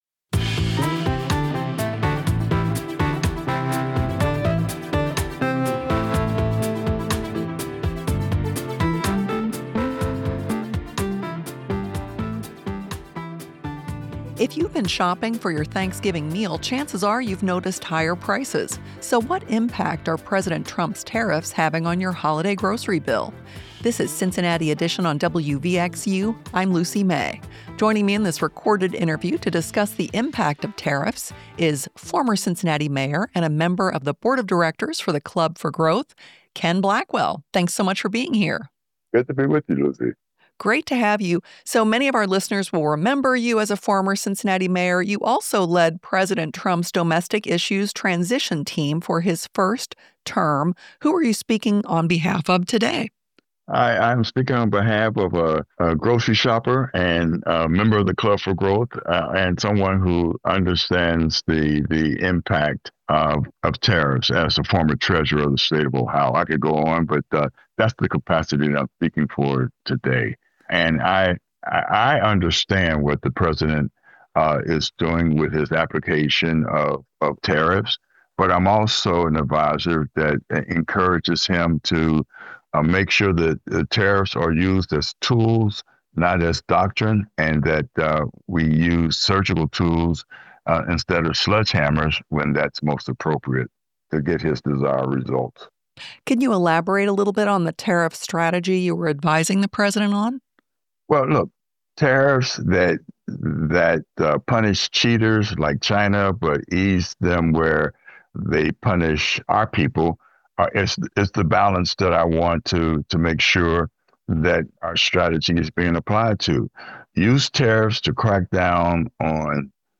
From host Andrew Hunter Murray and The Skewer's Jon Holmes comes Radio 4’s newest Friday night comedy The Naked Week, with a blend of the silly and serious.